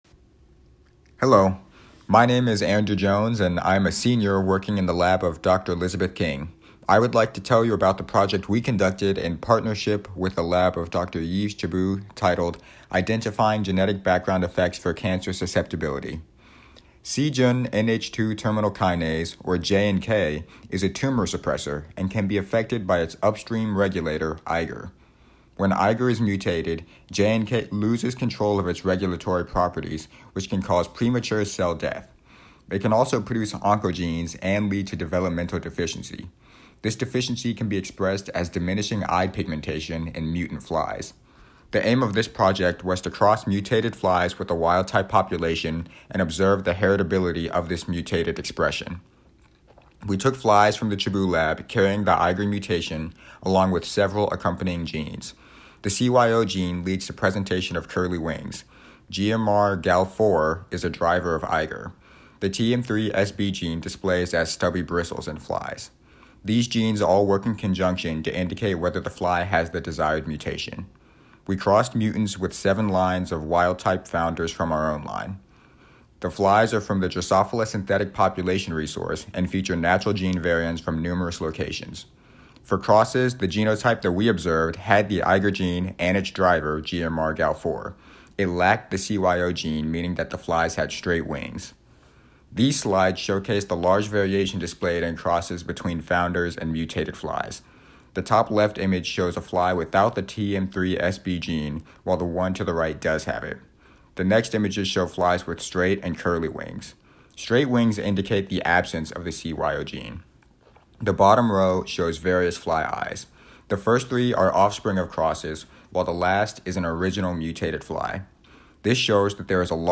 Virtual presentation at the Spring 2021 University of Missouri Undergraduate Research and Creative Achievements Forum, held April 2021.